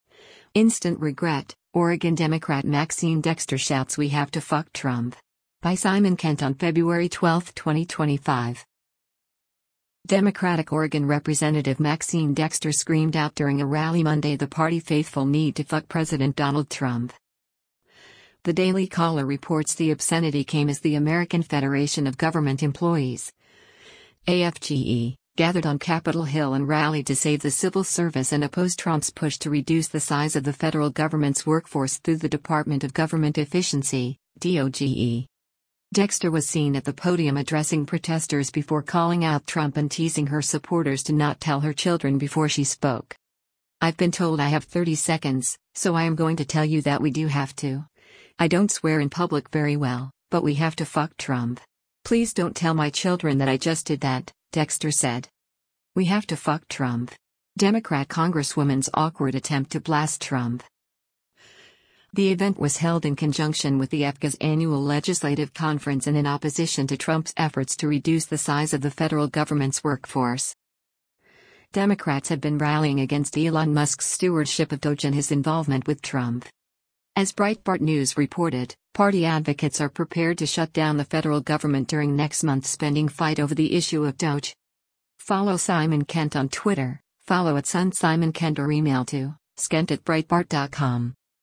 Democratic Oregon Rep. Maxine Dexter screamed out during a rally Monday the party faithful need to “fuck” President Donald Trump.
Dexter was seen at the podium addressing protesters before calling out Trump and teasing her supporters to not “tell her children” before she spoke.